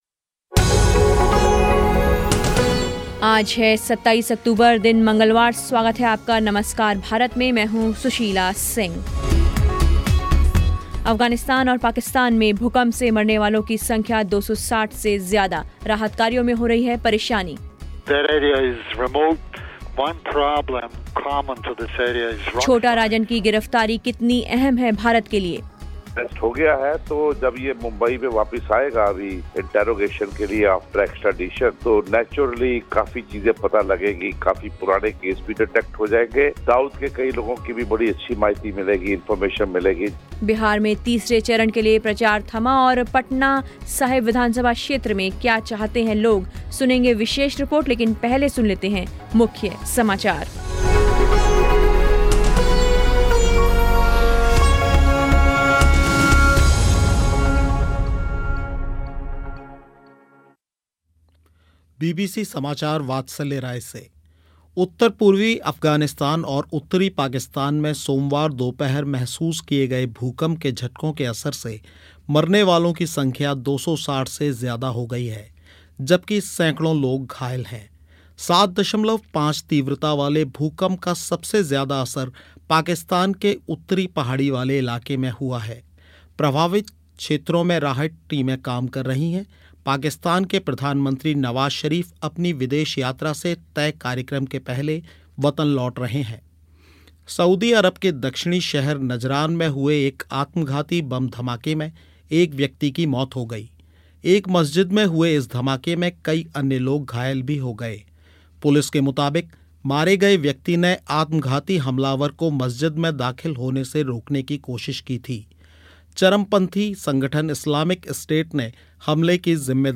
विशेष रिपोर्ट